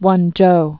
(wŭnjō) also Wen·chow (wĕnchou, wŭnjō)